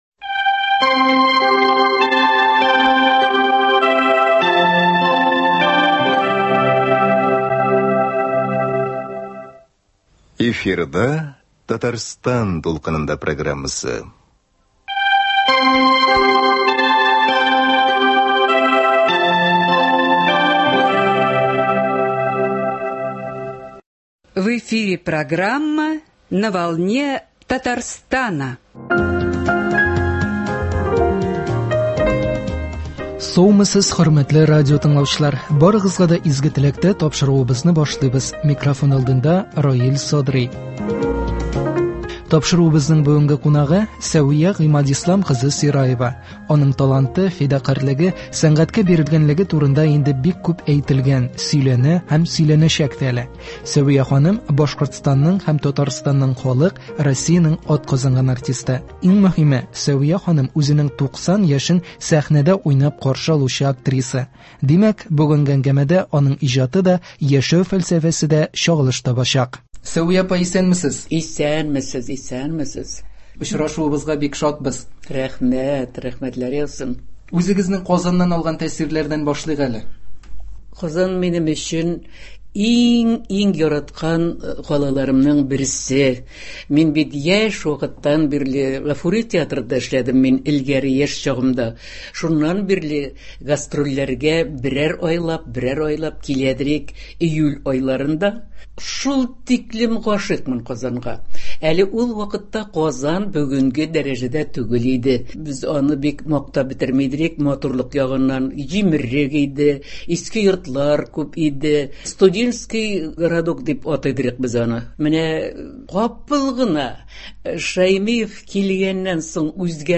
Бүгенге әңгәмәдә аның иҗаты да, яшәү фәлсәфәсе дә чагылыш табачак.